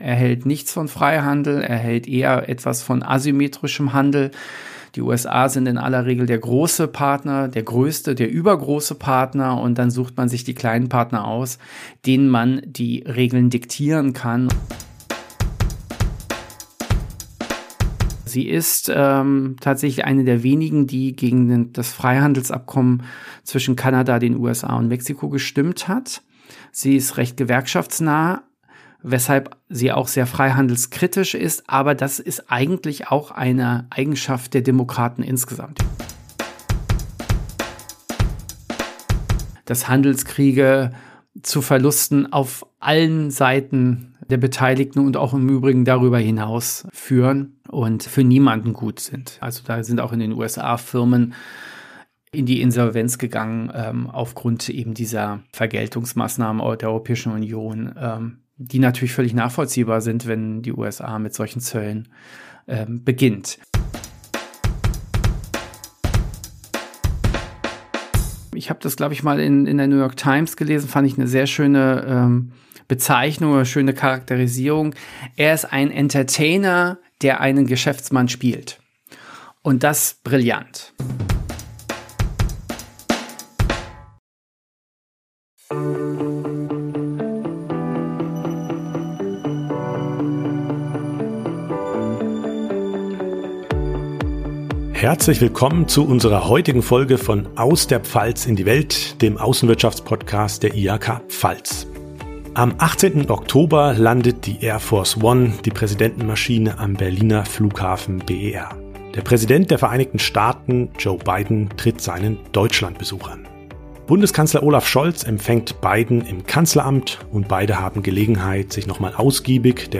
Interview ~ IHK-Lateinamerika-Briefing Podcast